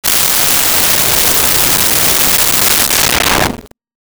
Alien Woosh 03
Alien Woosh 03.wav